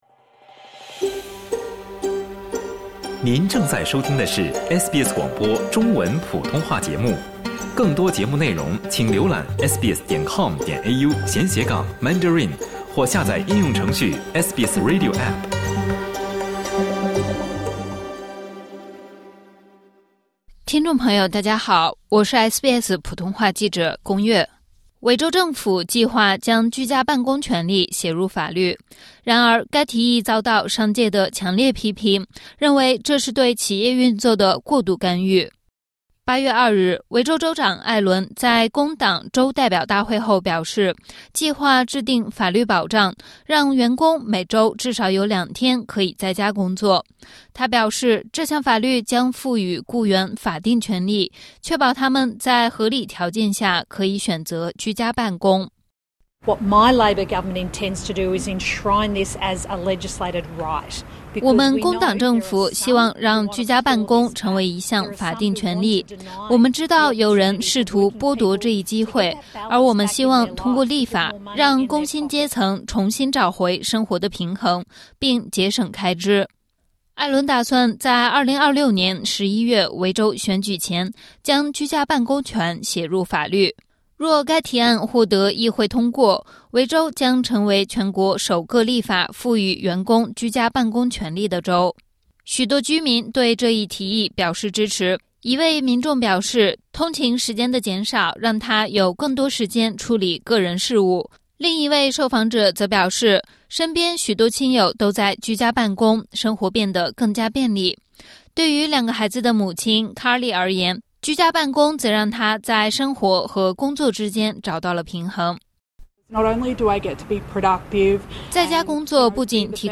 维州政府计划将居家办公权写入法律，让员工每周至少有两天可以在家工作。然而该提议遭到商界的强烈批评，认为这是政府对企业运作的“过度干预”。点击 ▶ 收听完整报道。